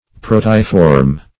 Search Result for " proteiform" : The Collaborative International Dictionary of English v.0.48: Proteiform \Pro*te"i*form\, a. (Zool.)